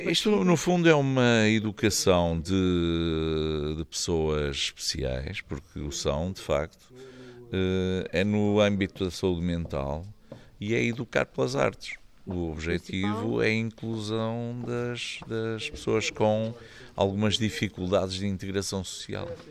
O objetivo é desenvolver competências socio-emocionais nos jovens, destinado a 600 crianças, que se encontrem em vulnerabilidade social entre os 10 e os 19 anos, do 2º e 3º ciclos, tal como explica Benjamim Rodrigues, presidente da câmara Municipal de Macedo de Cavaleiros: